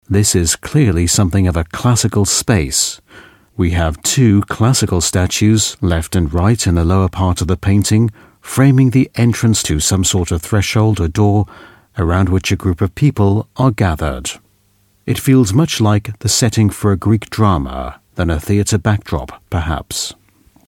Native Speaker
Englisch (UK)
Audioguides